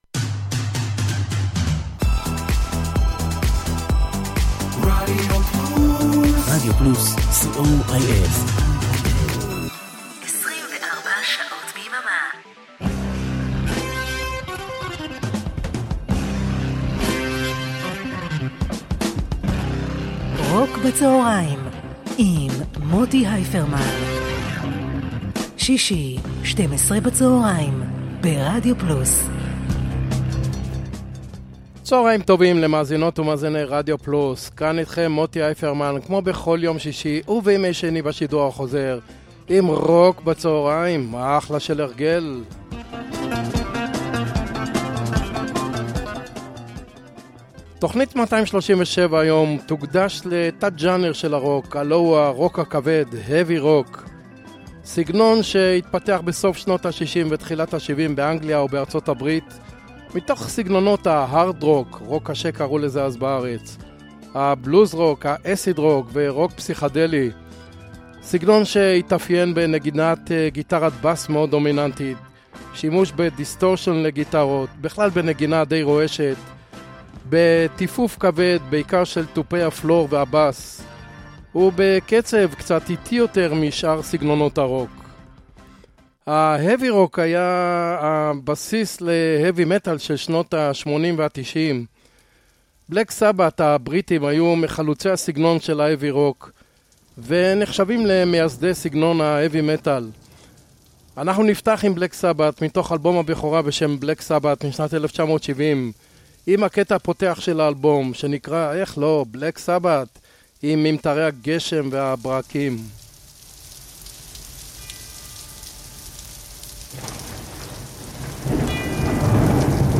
blues rock classic rock